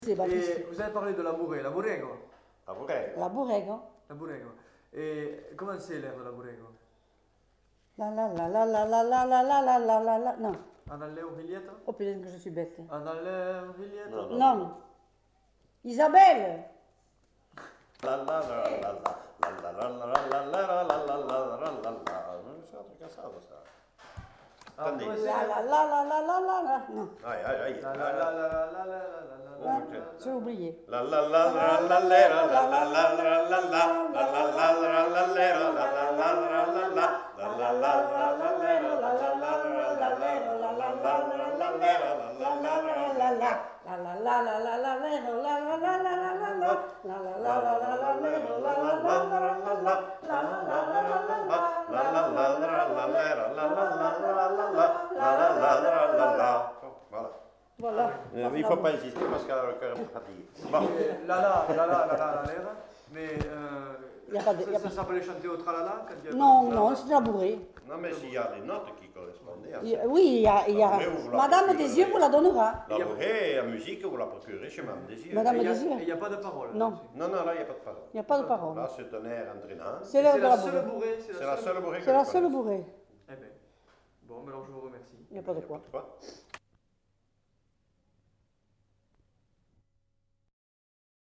Aire culturelle : Couserans
Genre : chant
Effectif : 2
Type de voix : voix d'homme ; voix de femme
Production du son : fredonné
Danse : bourrée